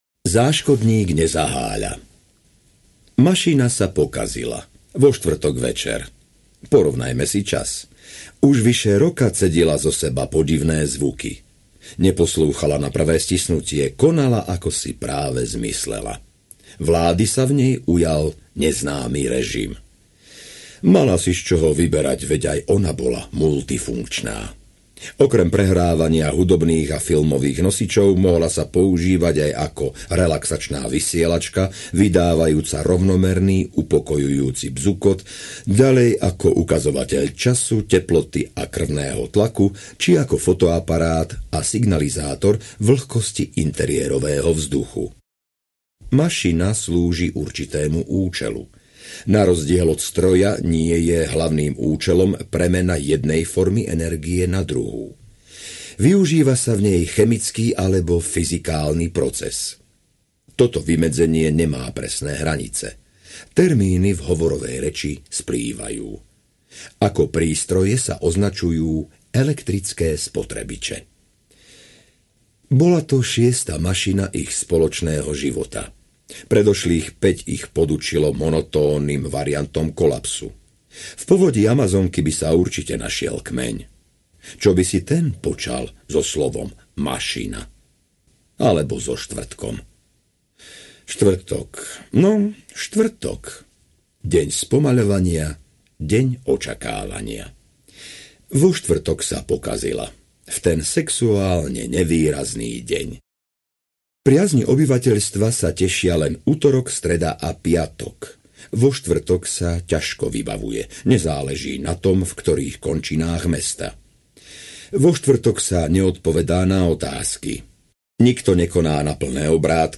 Želáte si novú kúpeľňu? audiokniha
Ukázka z knihy